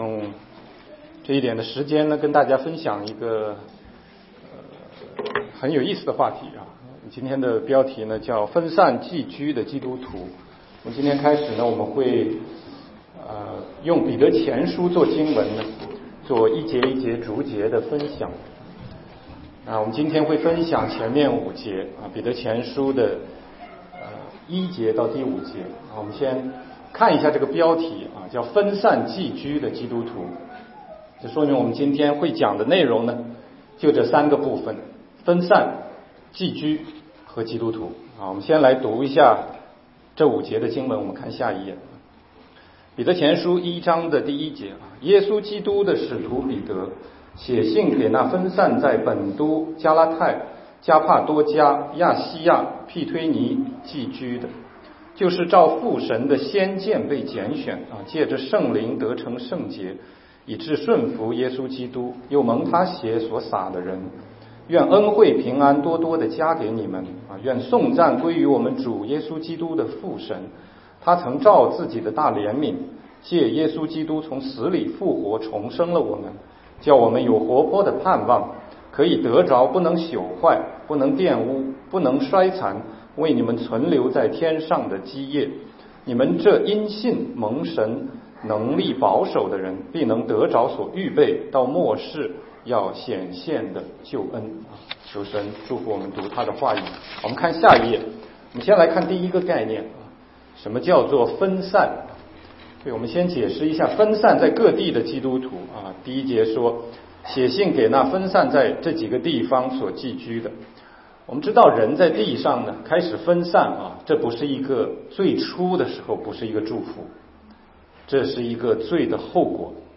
全中文讲道与查经